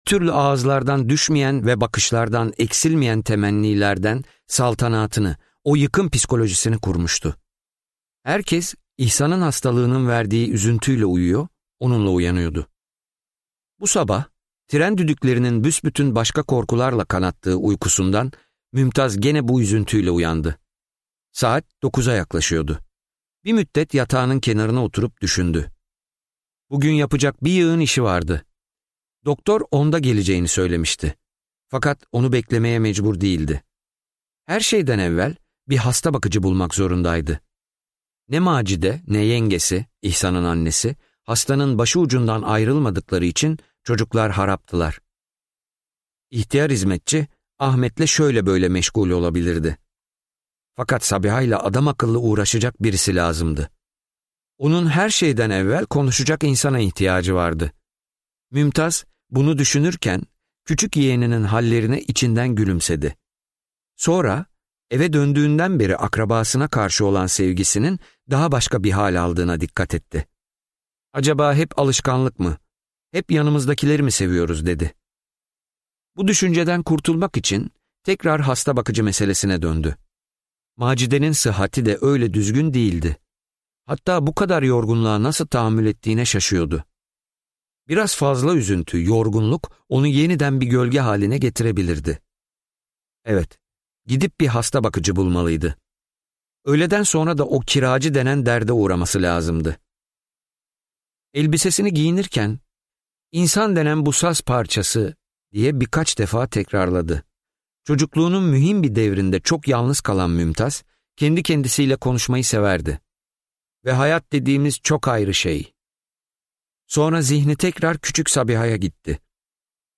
Huzur - Seslenen Kitap